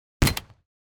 Armor Break 2.wav